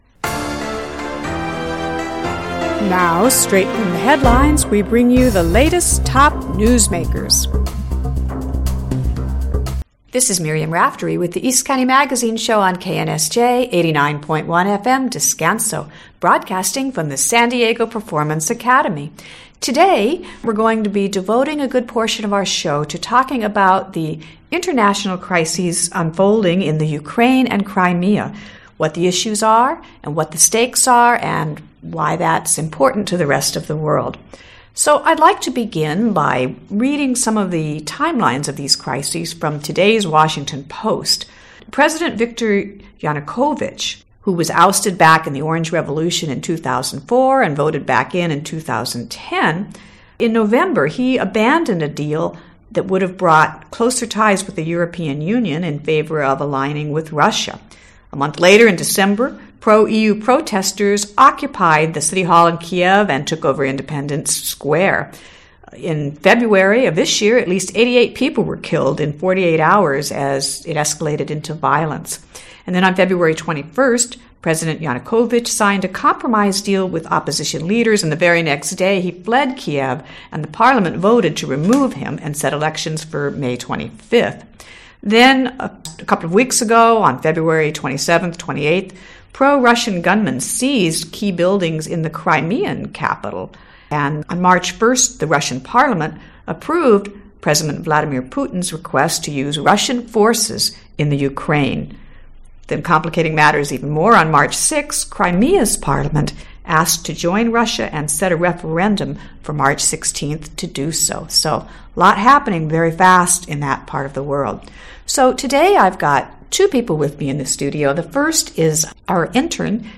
KNSJ radio. Listen to the interview: Audio